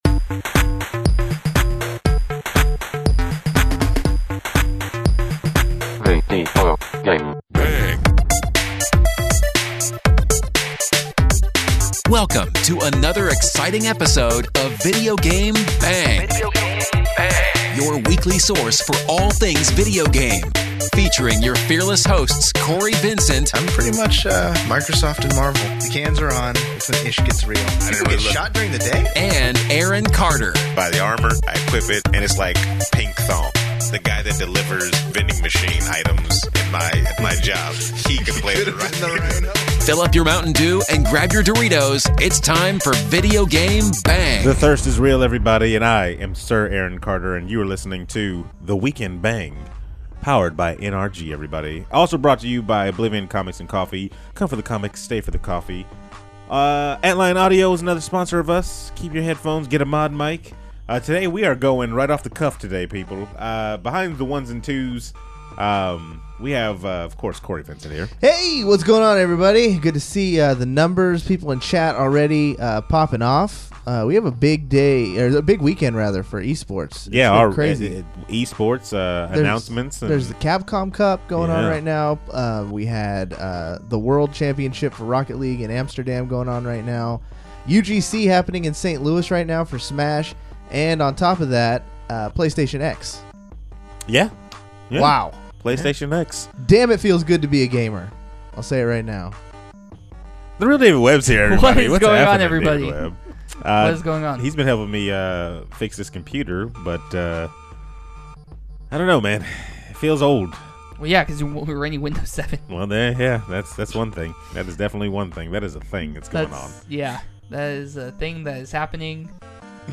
The Weekend BANG! Is just about as live as you can get, cause on this week's episode we go straight off the cuff, with no notes at all.